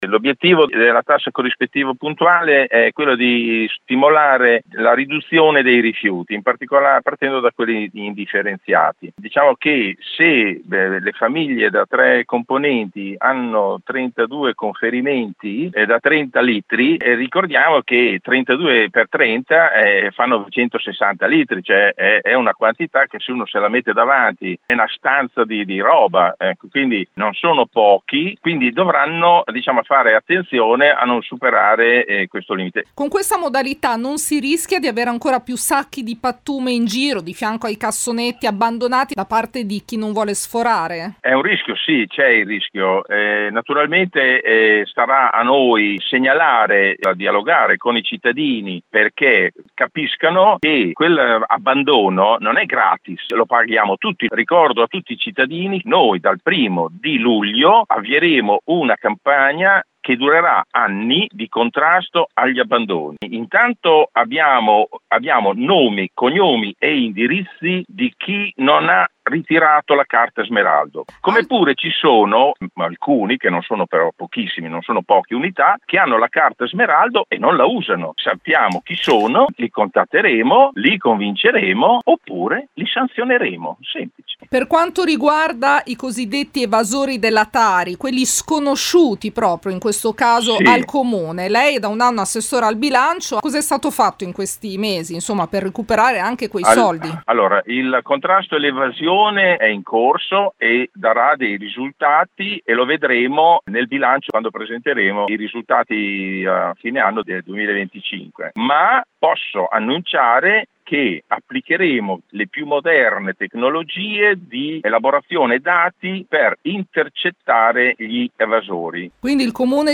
Raccolta rifiuti e tariffa puntuale: parla l'assessore Molinari